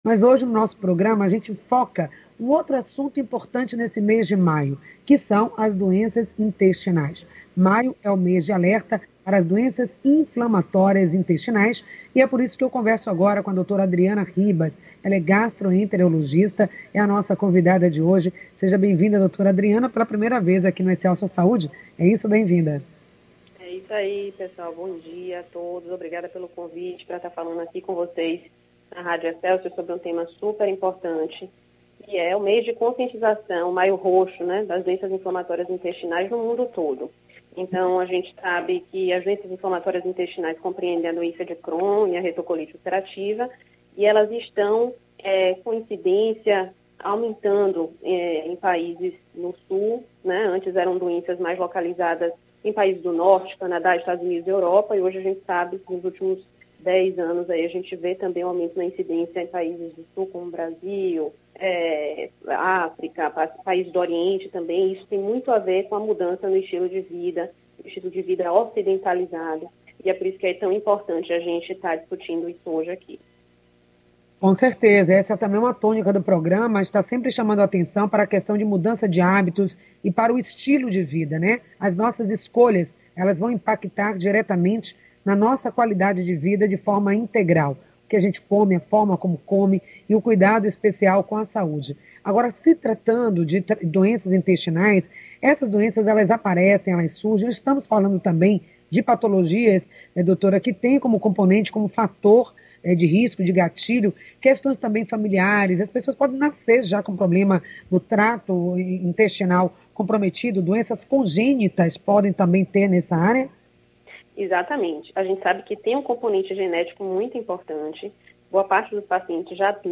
O tema da entrevista foi: Doenças intestinais.